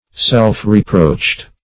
Search Result for " self-reproached" : The Collaborative International Dictionary of English v.0.48: Self-reproached \Self`-re*proached"\, a. Reproached by one's own conscience or judgment.